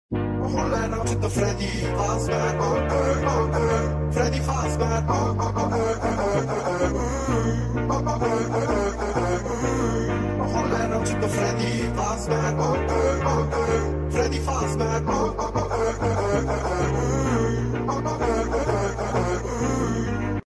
freddy-fazbear-doyalike-x-resonace-made-with-Voicemod-technology.mp3